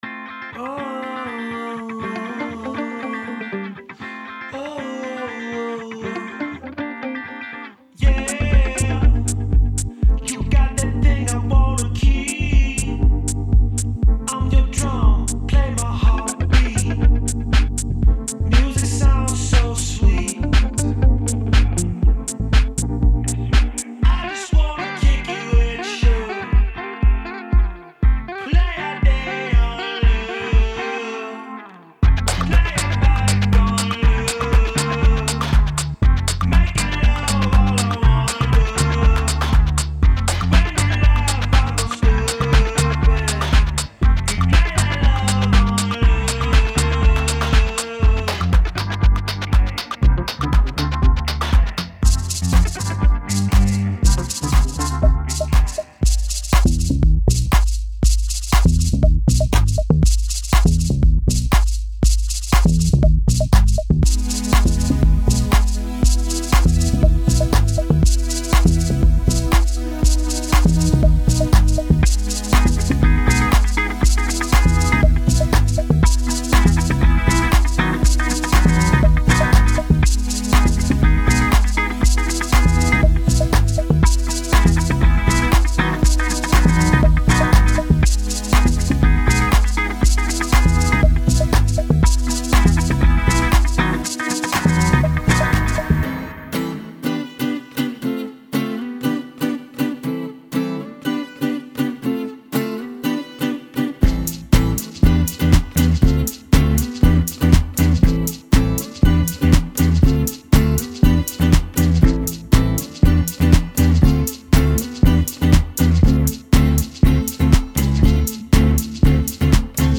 Genre:Indie Pop
デモサウンドはコチラ↓
36 Vocal Loops
26 Electric Guitar Loops
26 Synth Loops